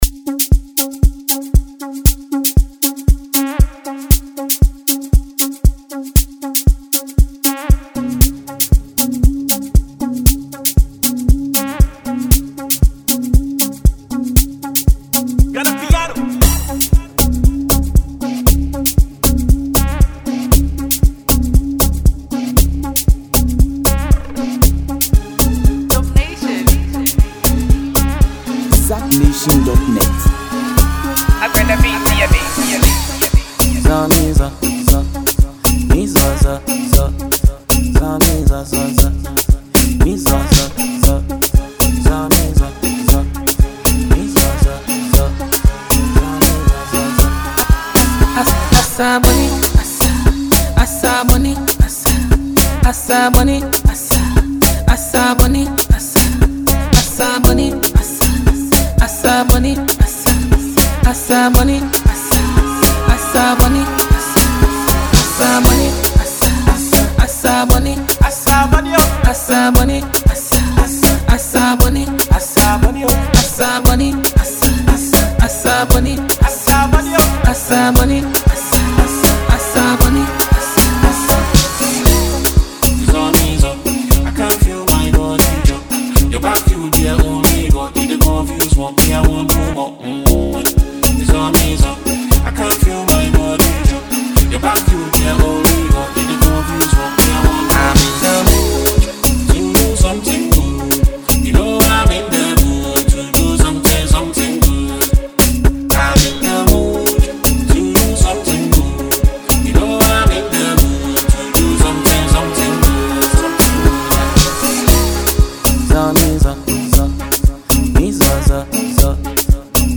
a Ghanaian musical duo